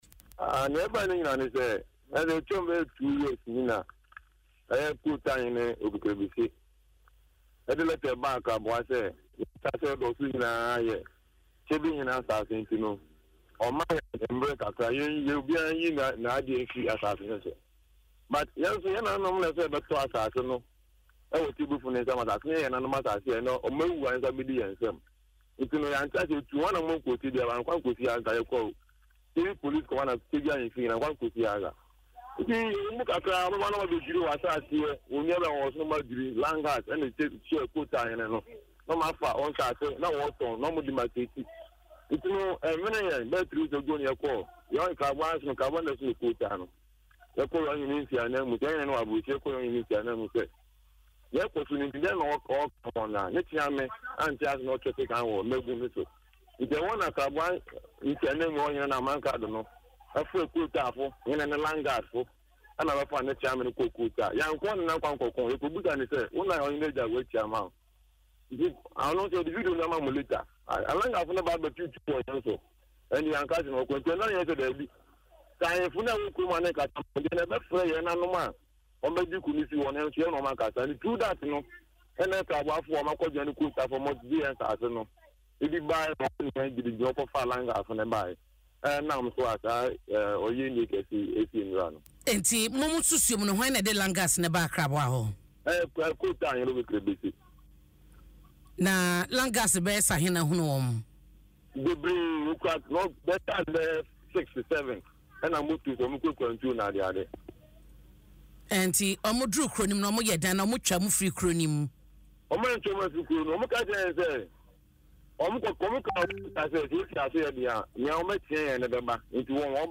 a resident who spoke on Adom FM’s Dwaso Nsem